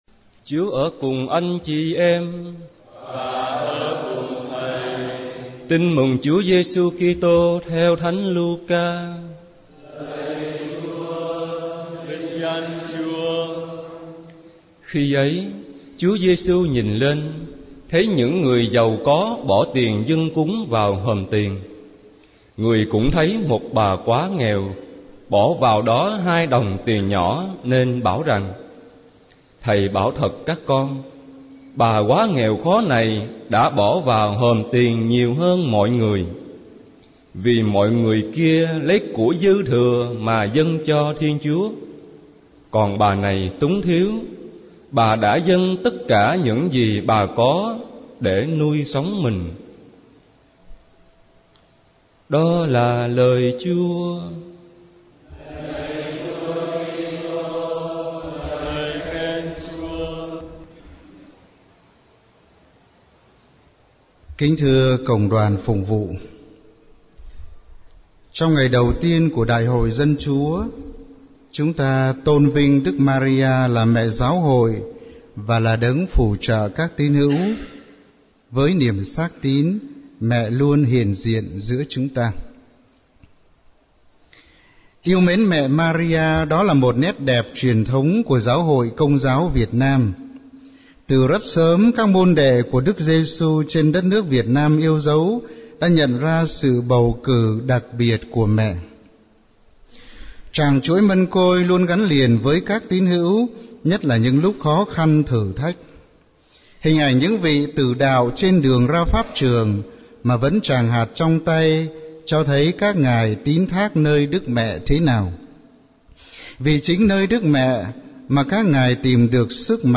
Tin mừng - Bài giảng Lễ sáng 22.11 - Đại Hội Dân Chúa 2010